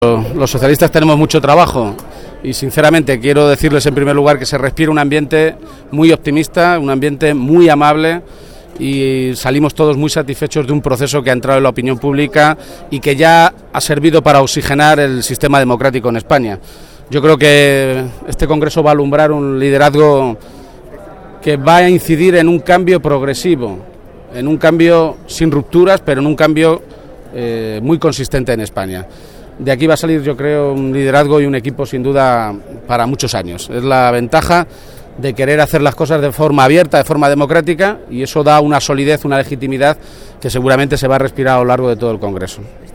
García-Page se pronunciaba de esta manera esta mañana, en Madrid, a su llegada al Congreso Extraordinario en el que el PSOE de toda España ratificará a Pedro Sánchez como nuevo secretario general y elegirá a su nueva dirección.